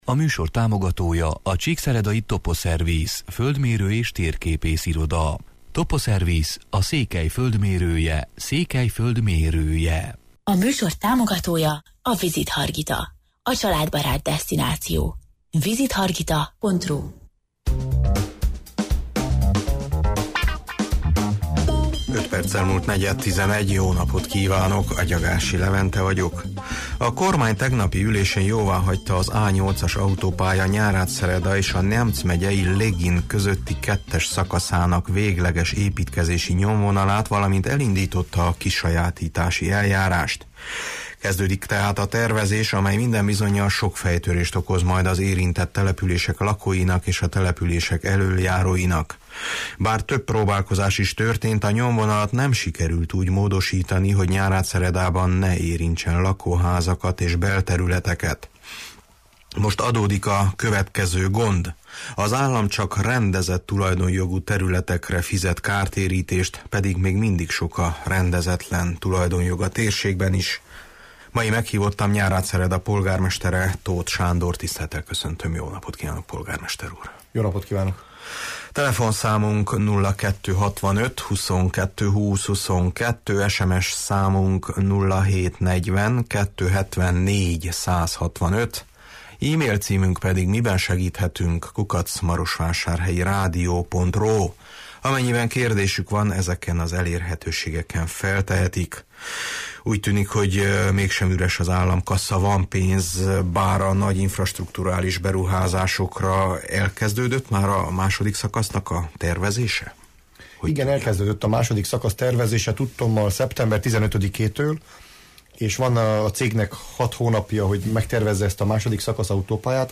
Meghívottam Nyárádszereda polgármestere, Tóth Sándor: